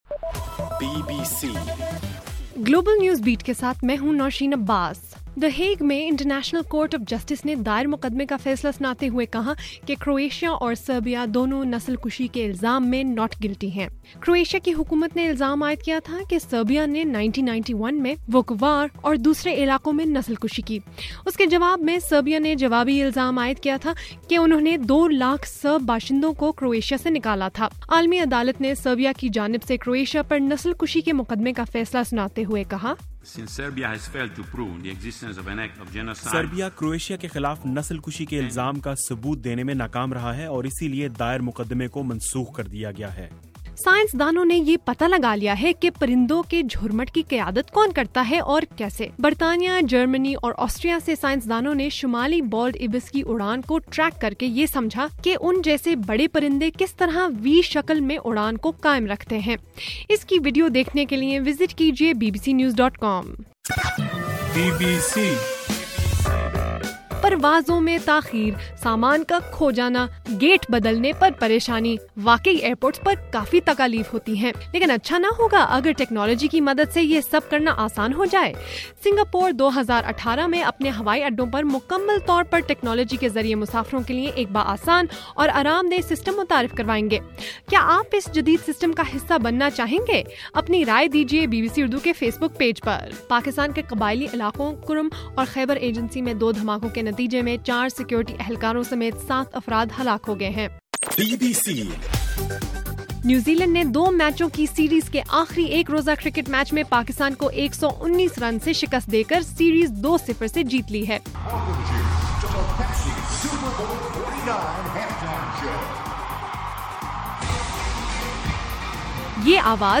فروری 3: رات 9 بجے کا گلوبل نیوز بیٹ بُلیٹن